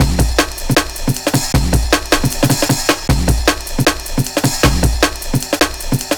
Index of /90_sSampleCDs/Zero-G - Total Drum Bass/Drumloops - 1/track 03 (155bpm)